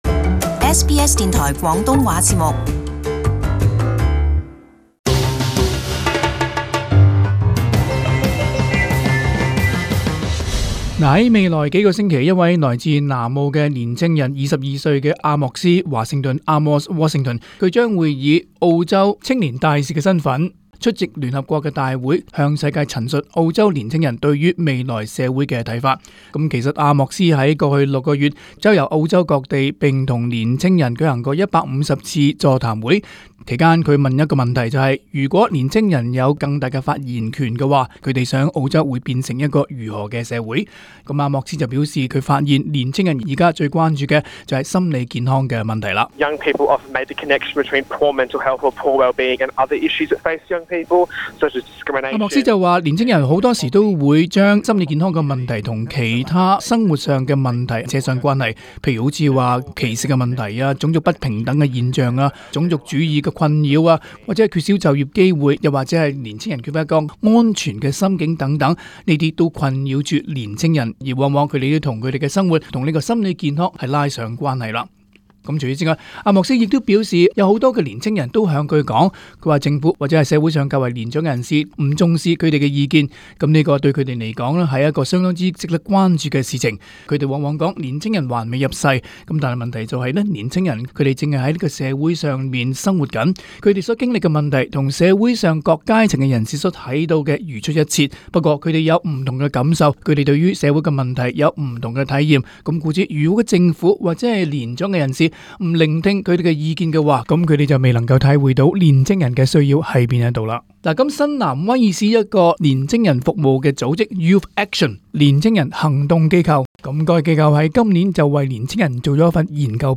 【時事報導】澳洲年青人對社會的看法與期望